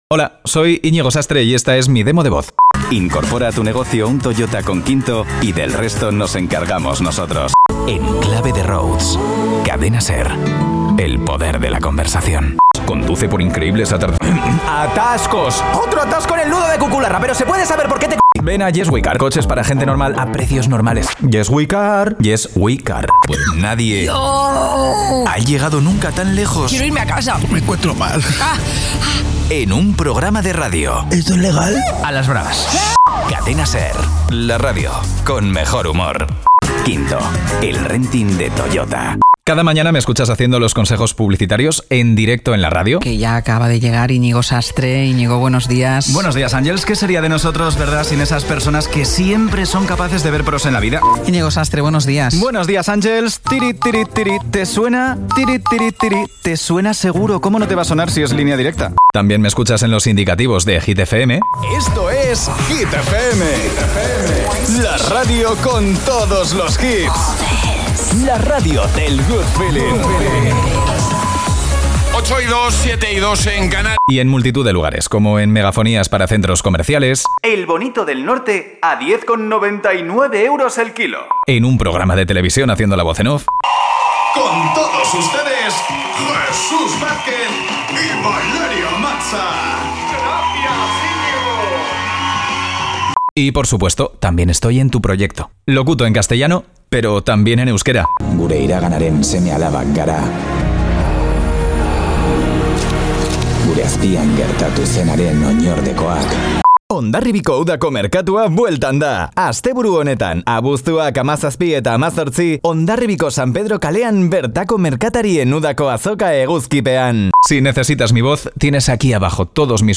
Locutor de publicidad en euskera y castellano. Publicidad en radio, spots de televisión y megafonías.
¿Nos conocemos? Escucha mi demo...